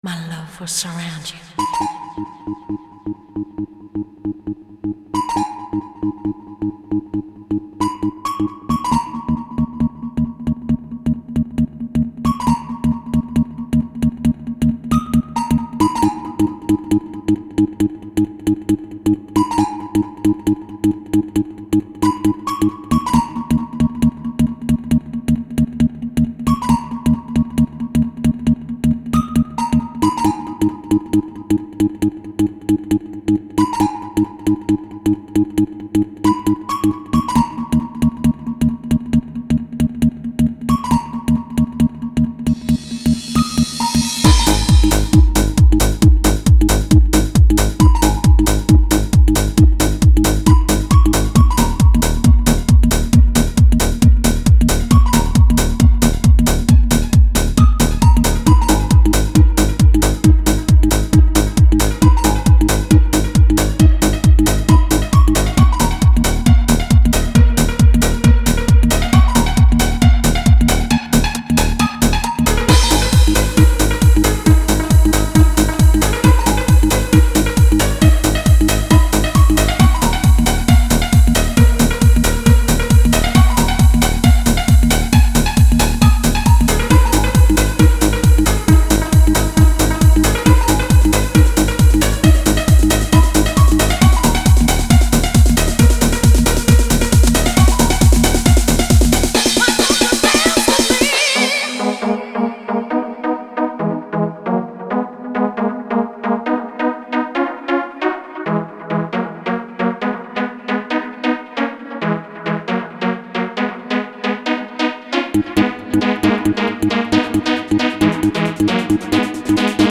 Genre: Hard Trance, Techno, Rave, Hardcore, Dance.